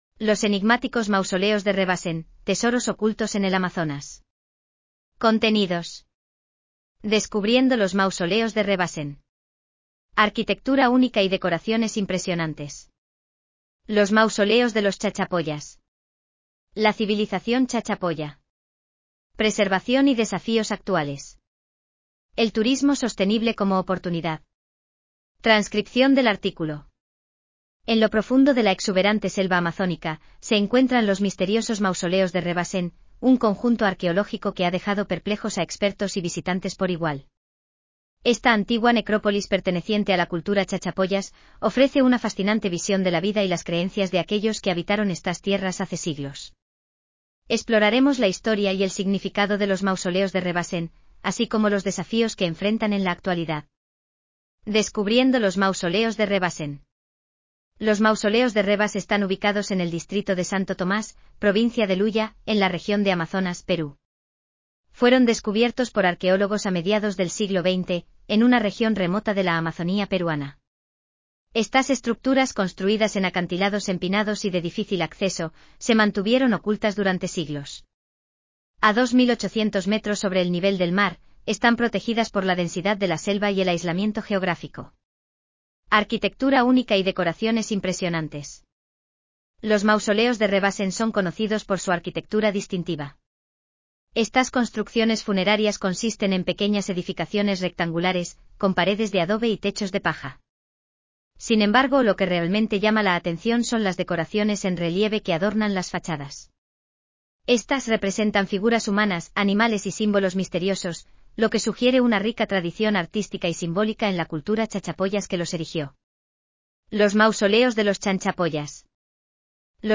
Transcripción en audio del artículo